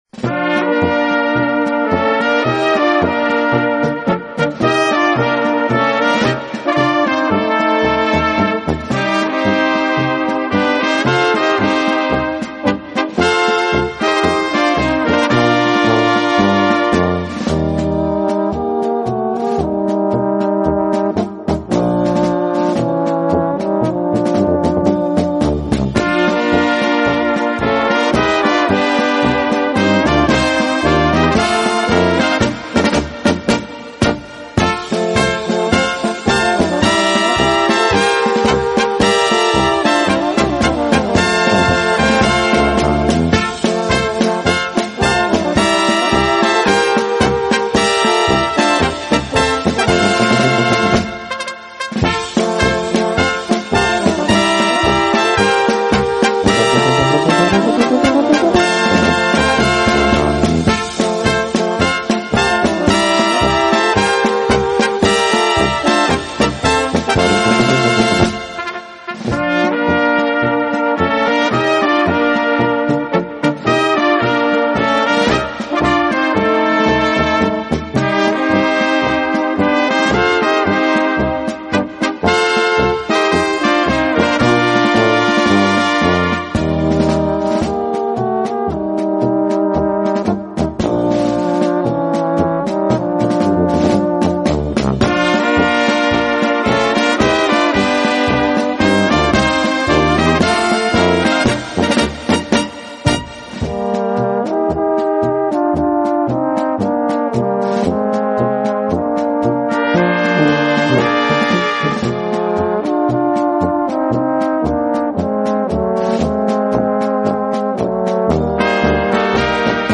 Gattung: Polka für kleine Blasmusik
Besetzung: Kleine Blasmusik-Besetzung